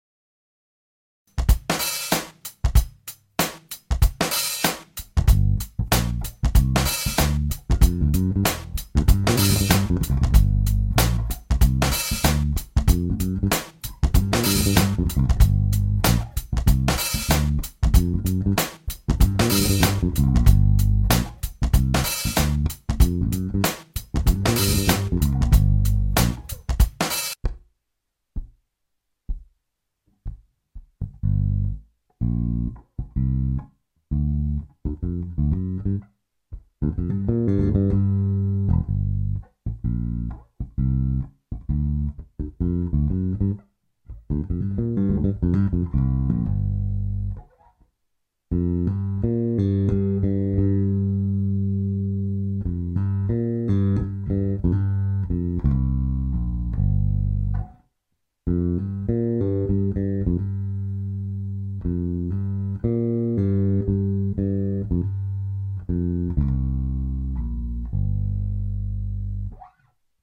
L117 Open string bass lick in G